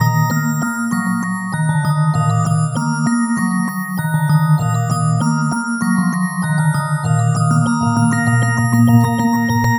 Hands Up - Wind Chime.wav